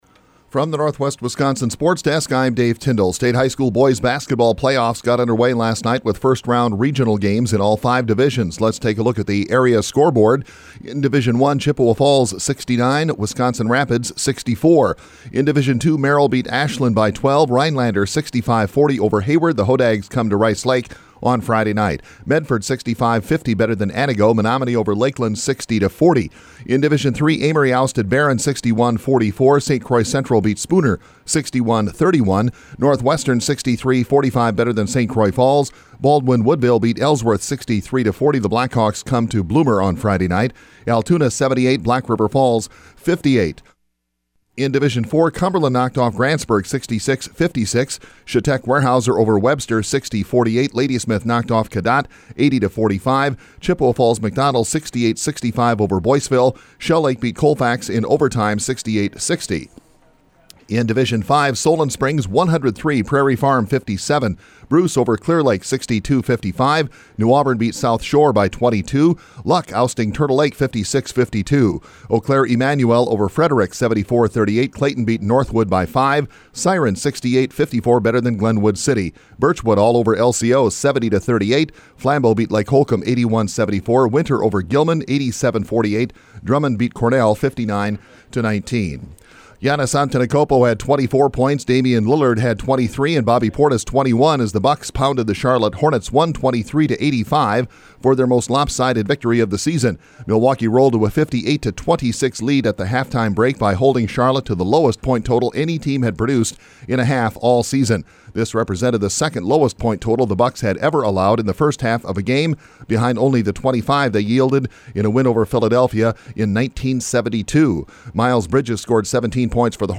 Today’s sportscast from the Northwest Wisconsin Sports Center.